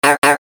Gemafreie Sounds: Spielzeug
mf_SE-8179-toy_car_honk.mp3